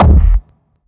GS Phat Kicks 013.wav